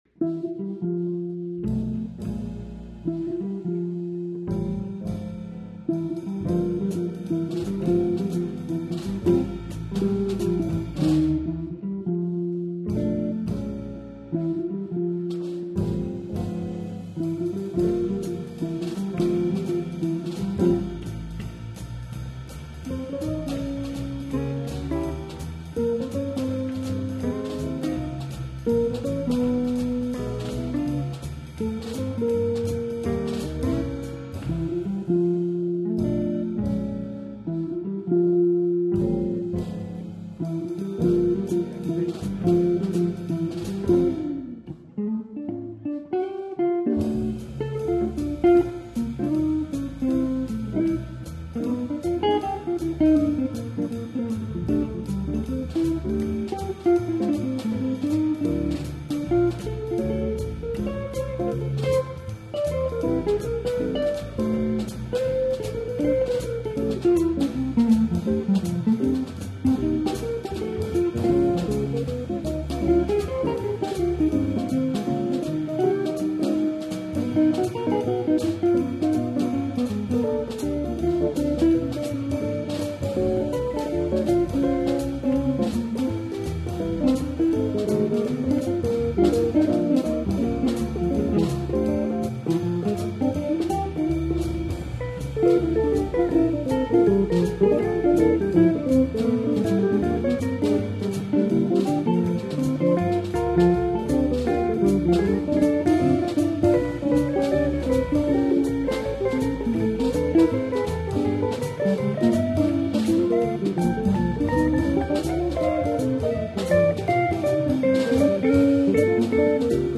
U of I Jazz Guitar Ensemble - Fall 2006 Concert
Electric Guitar
Bass
Drums
Written by Chris Buzzelli